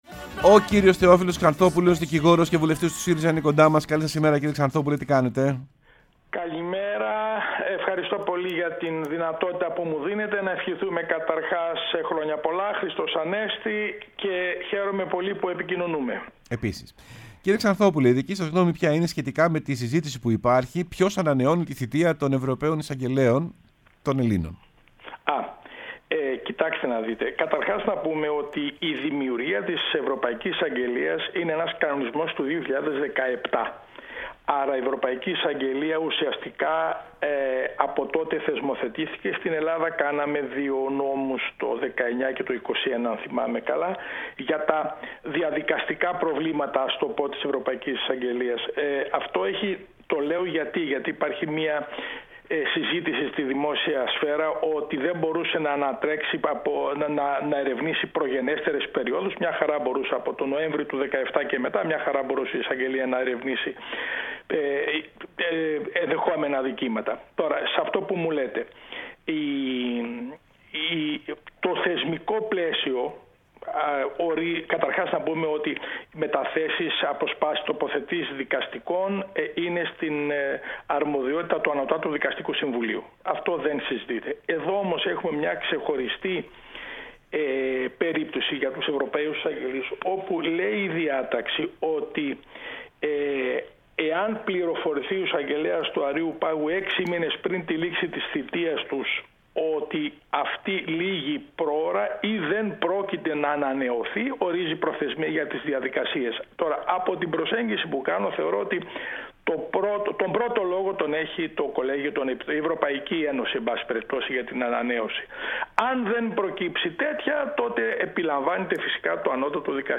Ο Θεόφιλος Ξανθόπουλος, Δικηγόρος και βουλευτής ΣΥΡΙΖΑ, μίλησε στην εκπομπή «Σεμνά και Ταπεινά»